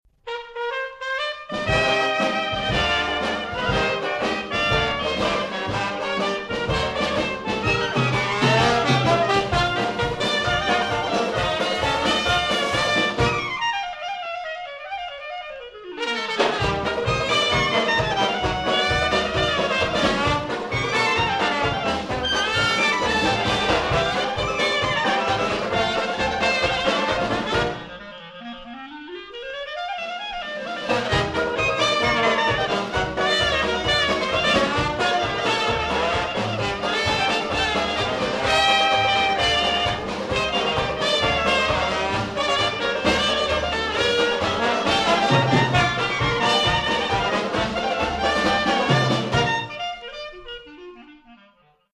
and he was smitten with Dixieland Jazz while very young.